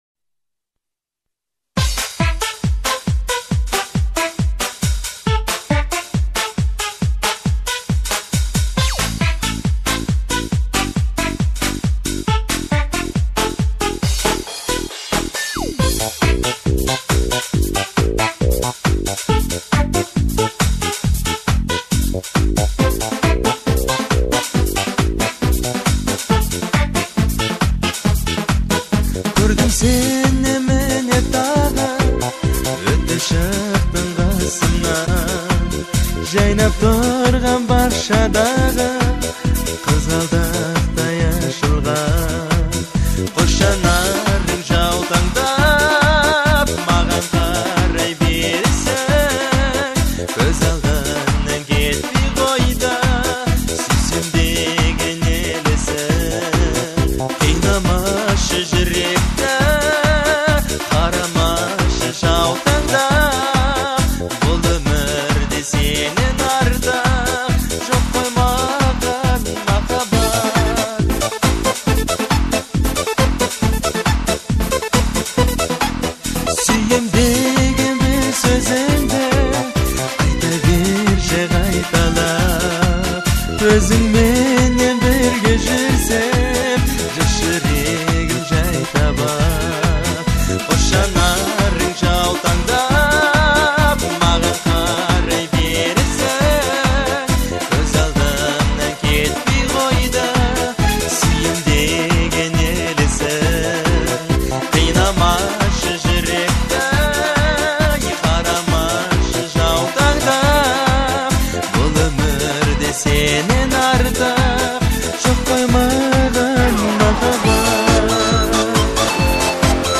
это эмоциональная баллада в жанре казахской поп-музыки